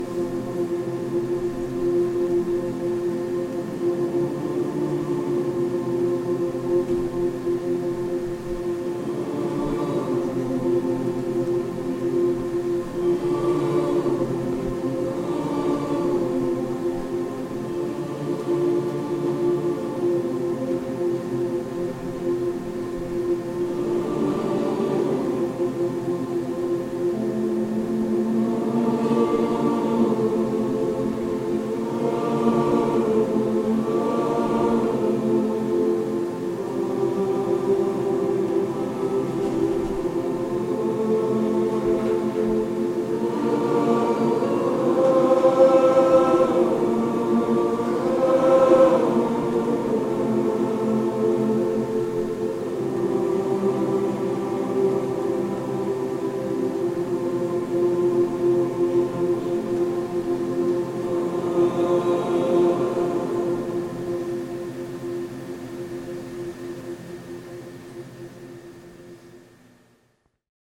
Geführtes Harmonie-Singen